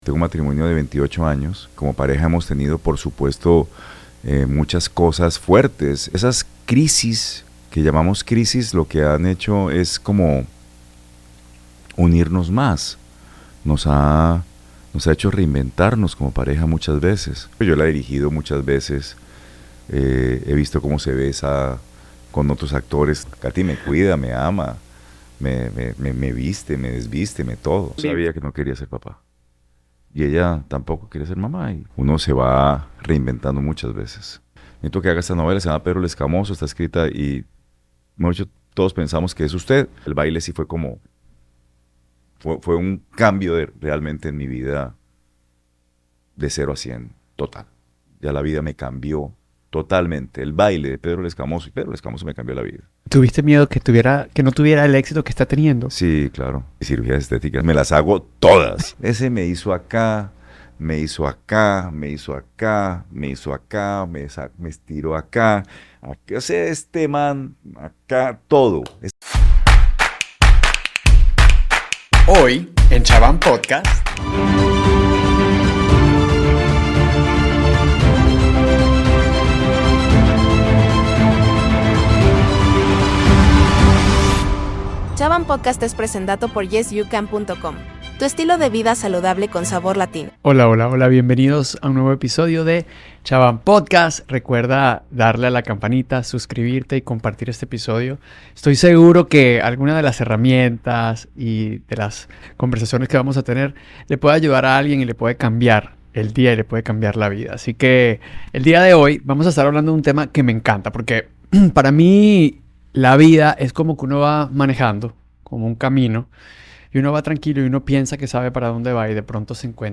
Hoy tengo el honor de presentar a un invitado muy querido por todos, un actor que ha dejado una huella imborrable en el mundo de las telenovelas. Se trata del gran Miguel Varoni, un ícono de la televisión latinoamericana,...